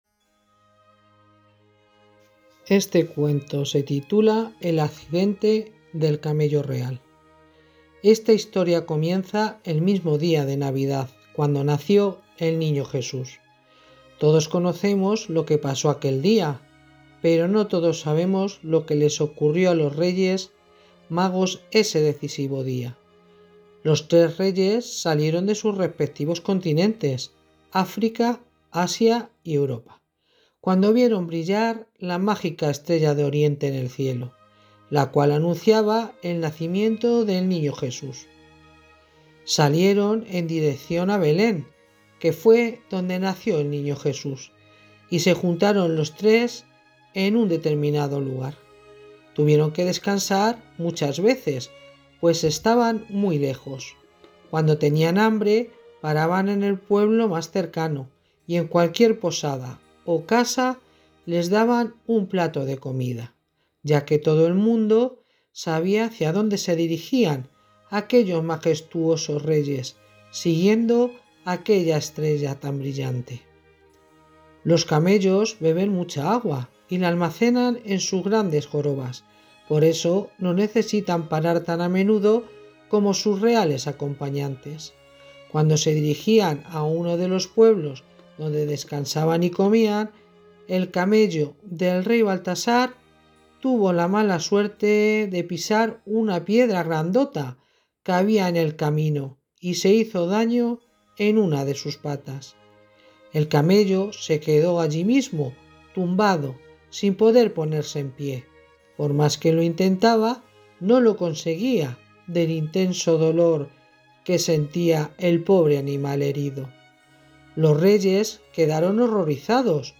Audiolibro "El accidente del Camello" en Español.
Audiolibro: El accidente del camello